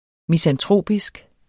Udtale [ misanˈtʁoˀbisg ]